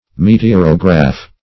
Search Result for " meteorograph" : The Collaborative International Dictionary of English v.0.48: Meteorograph \Me`te*or"o*graph\, n. [Meteor + -graph.] An instrument which registers meteorologic phases or conditions.